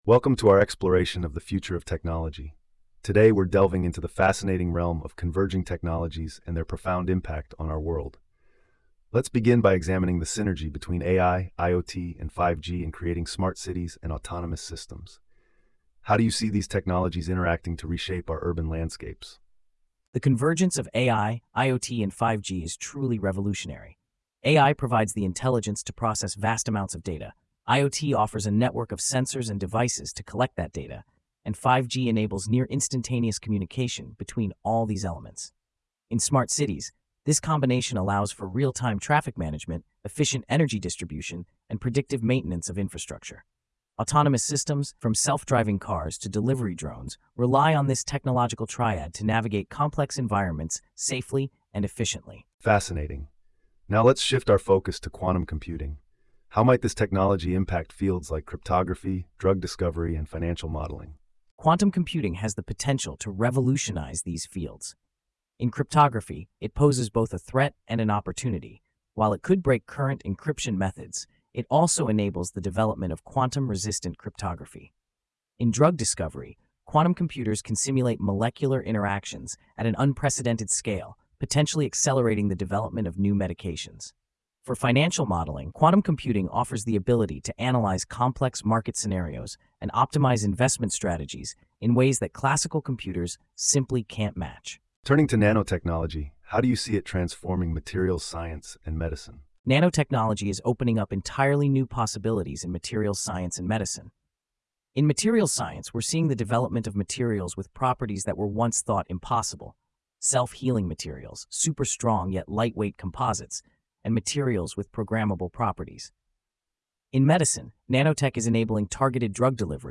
PodWaveAI - AI-Powered Podcast Generator